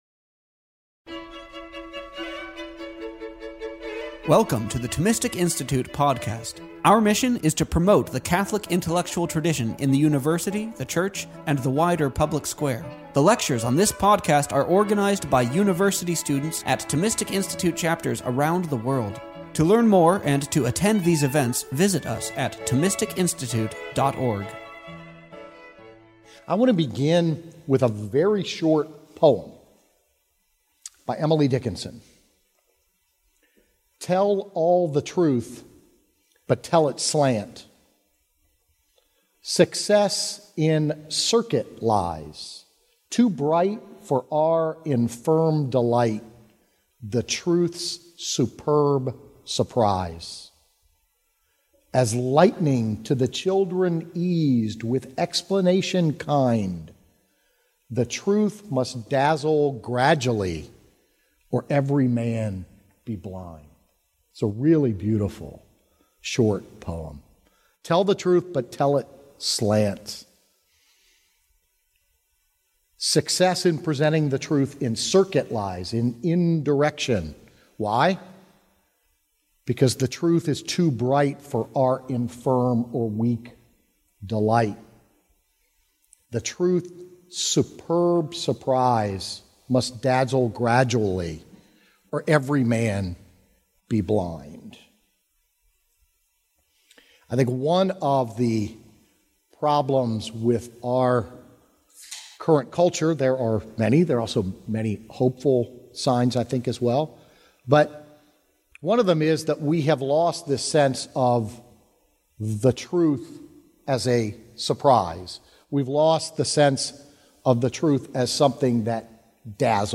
This lecture was given on September 12th, 2024, at University of Pittsburgh.